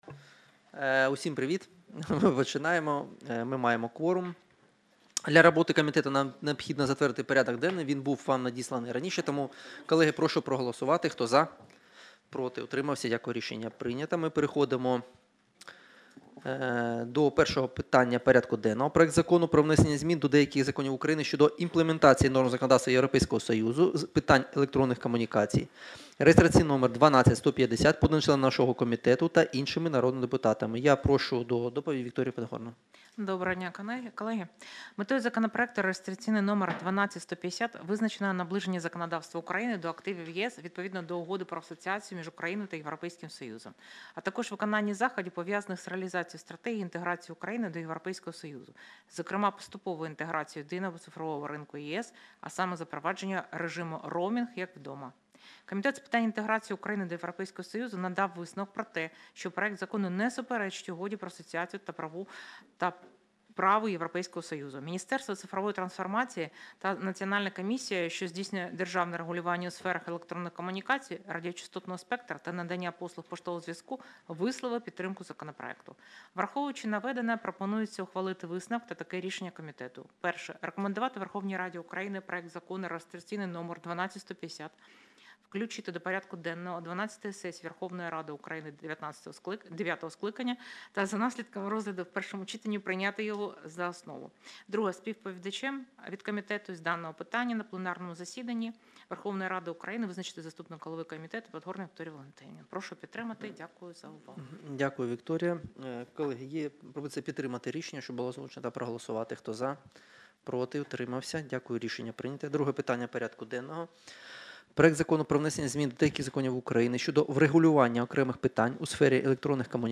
Аудіозапис засідання Комітету від 19.11.2024